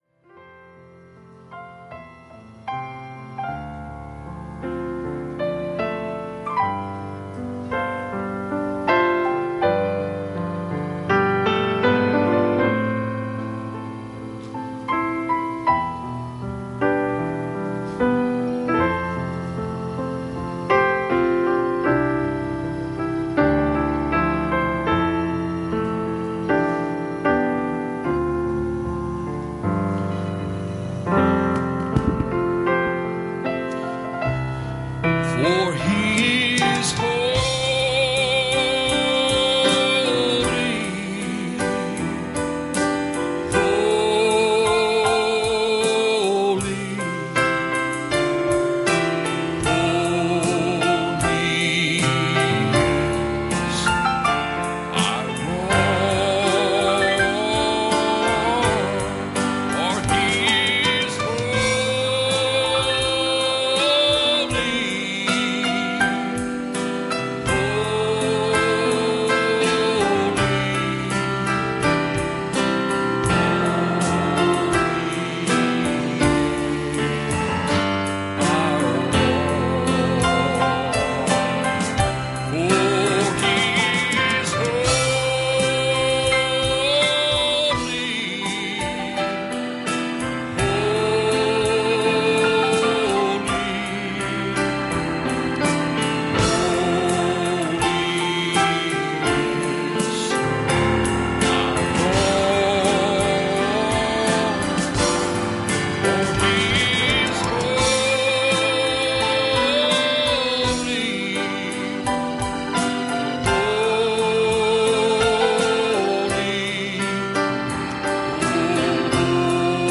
Passage: Revelation 2:18 Service Type: Sunday Evening